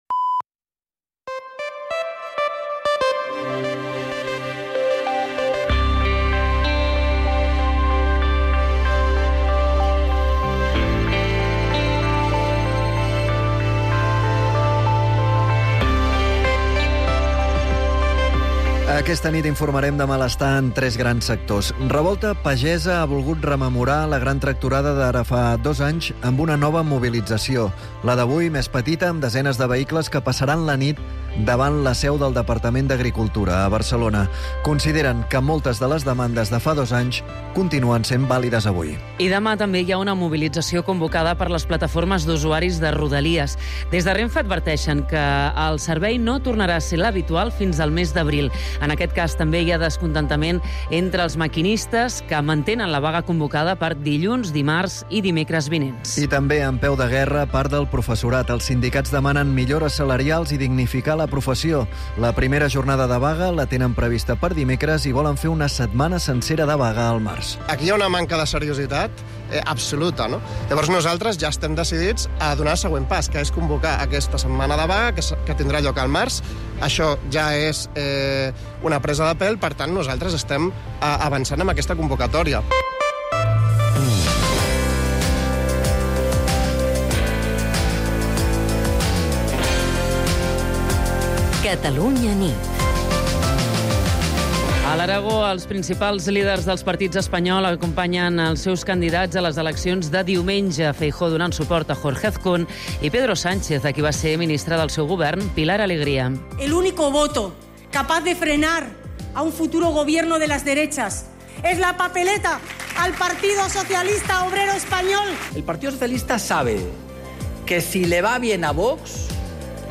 l’informatiu nocturn de Catalunya Ràdio